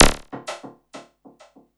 45-zap08.aif